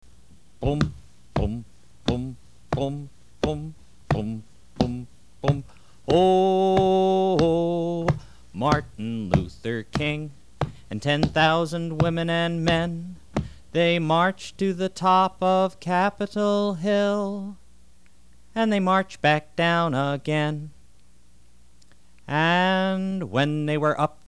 Action Song for Young Children
March in place to the beat.